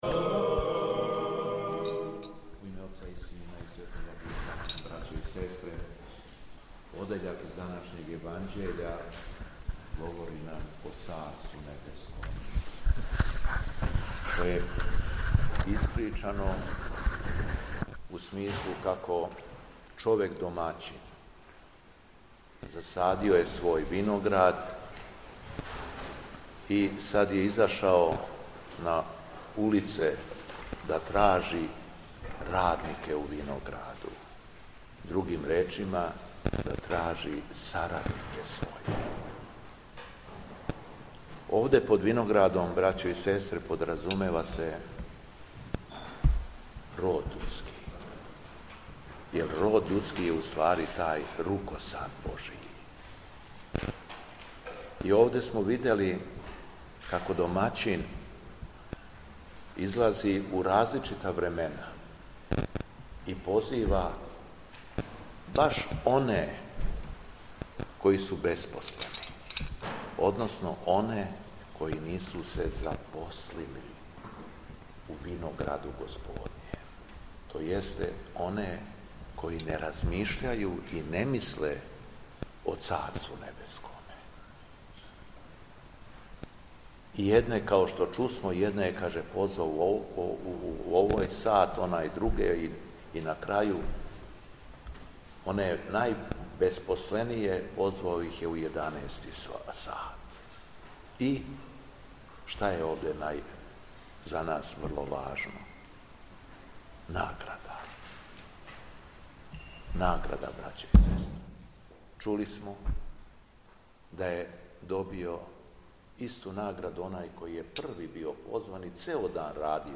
У четвртак 17. октобра 2024. године, Његово Високопресвештенство Митрополит шумадијски Г. Јован служио је Свету Литургију у Старој Цркви у Крагујевцу уз саслужење братства овога светога храма.
Беседа Његовог Високопреосвештенства Митрополита шумадијског г. Јована